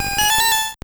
Cri de Porygon2 dans Pokémon Or et Argent.